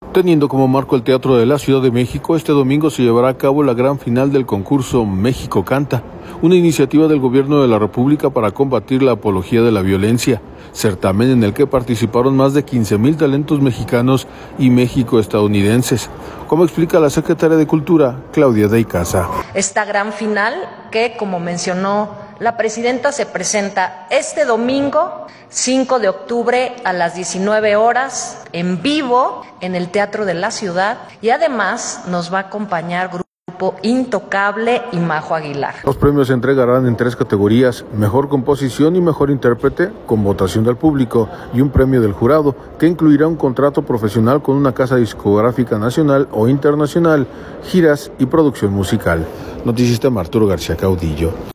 Teniendo como marco el Teatro de la Ciudad de México, este domingo se llevará a cabo la gran final del concurso México Canta, una iniciativa del Gobierno de la República para combatir la apología de la violencia, certamen en el que participaron más de 15 mil talentos mexicanos y mexicoestadounidenses, como explica la secretaria de Cultura, Claudia de Icaza.